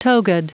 Prononciation du mot togaed en anglais (fichier audio)
Prononciation du mot : togaed